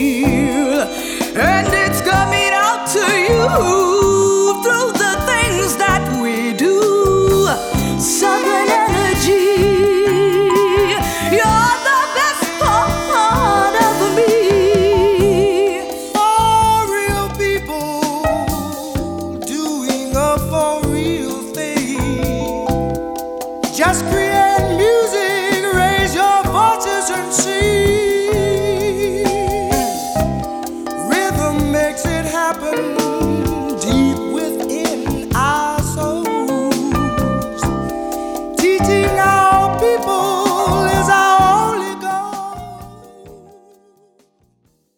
R'n'B and jazz collective
Funk